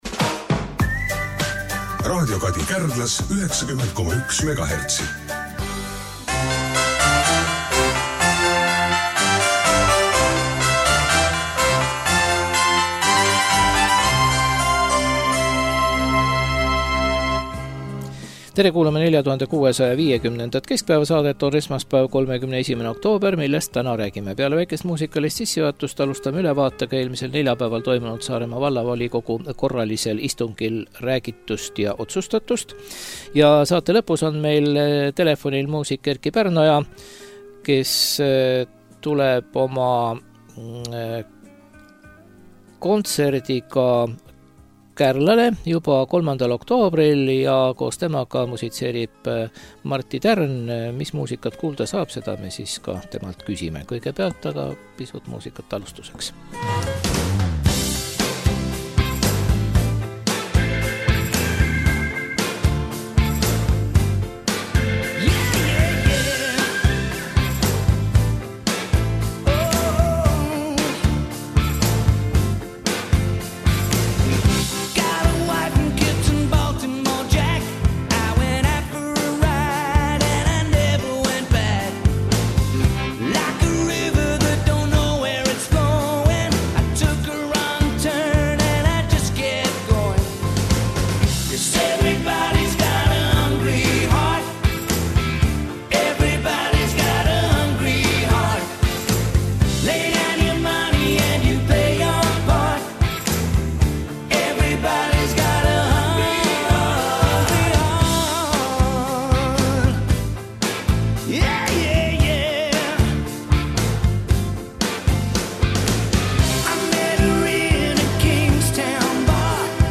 Telefoniintervjuu